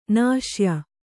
♪ nāśya